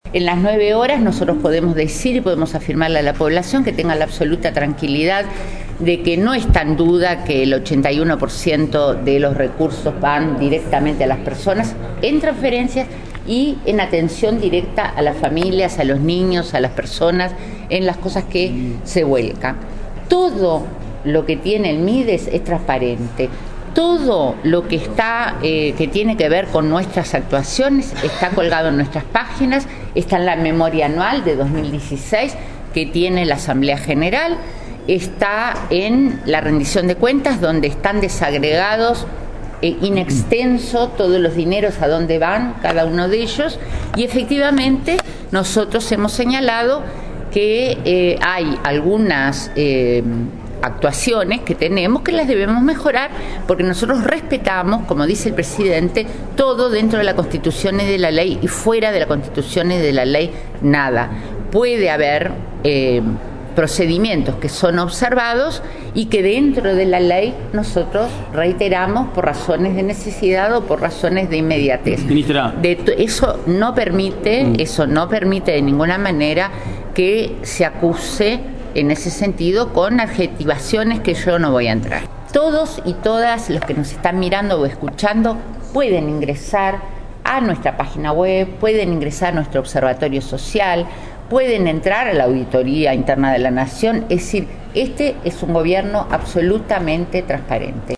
Ante la Comisión de Población de Diputados, la ministra de Desarrollo Social, Marina Arismendi, defendió la transparencia de los procesos en su cartera y aseguró que la mayor parte del presupuesto está destinado a transferencias y a la atención de personas en situación de vulnerabilidad. Recordó que es posible acceder a las actuaciones a través de la memoria anual, las rendiciones de cuentas y la web del ministerio.